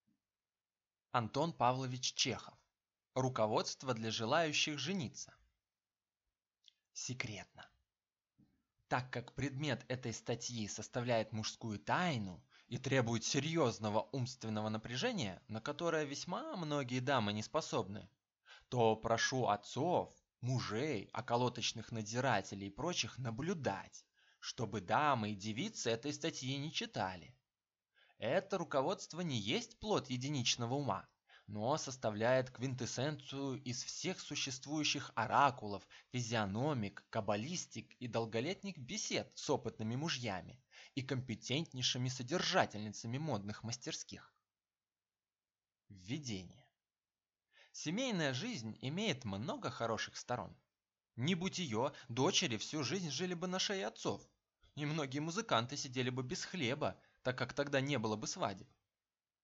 Аудиокнига Руководство для желающих жениться | Библиотека аудиокниг